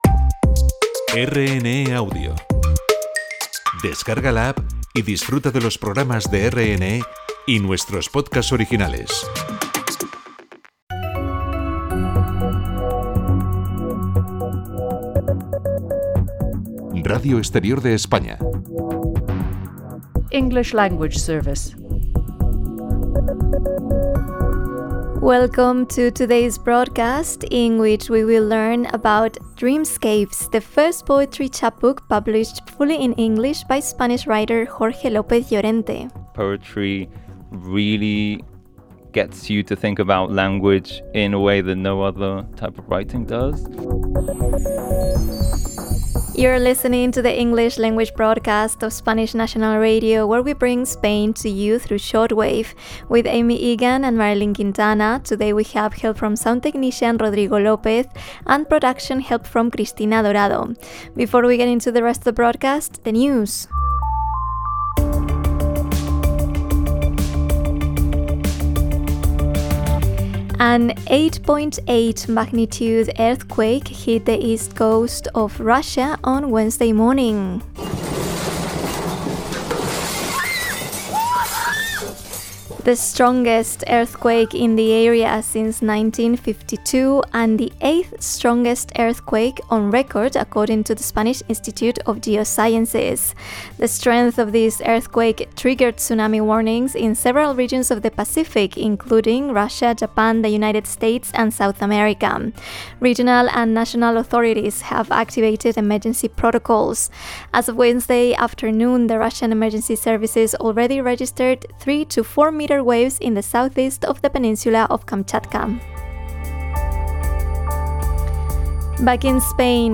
Radio interview about Dreamescapes on RNE